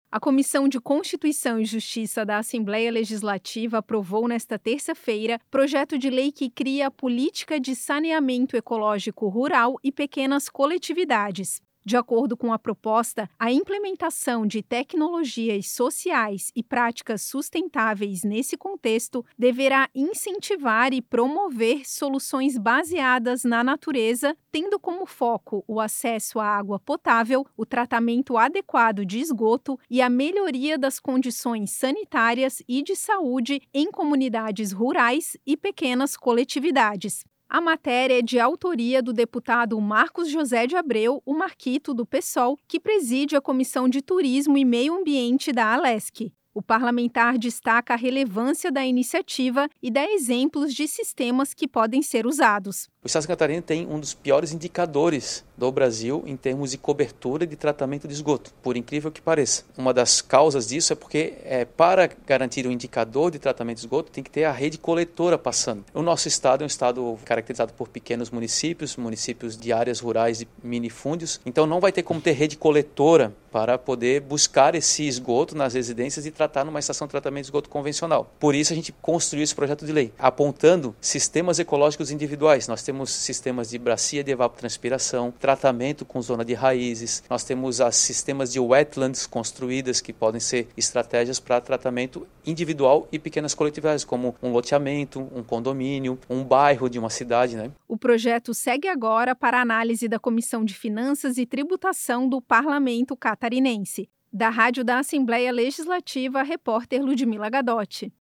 Entrevista com:
- deputado Marcos José de Abreu - Marquito (Psol), presidente da Comissão de Turismo e Meio Ambiente da Alesc e autor do PL 20/2024.